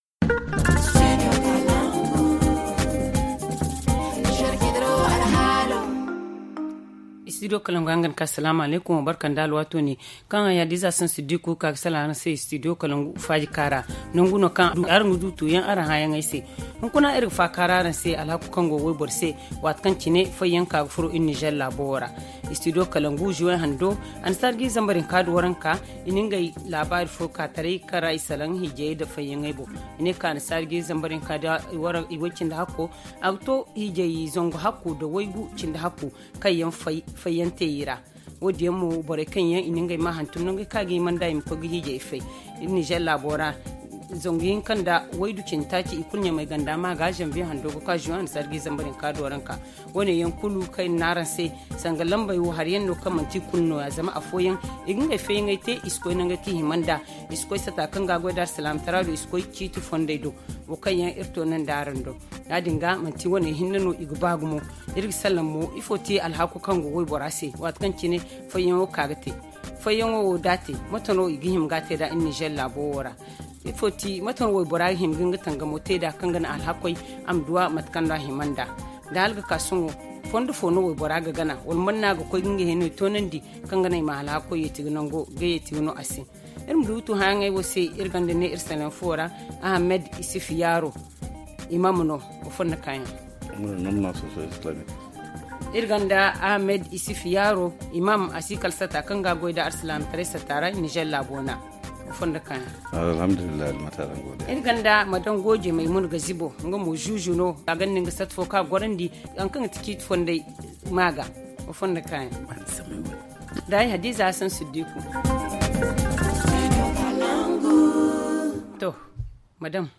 ZA Le forum en zarma https